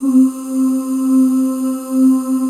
C3 FEM OOS.wav